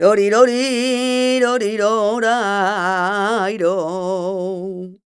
46b16voc-f#.aif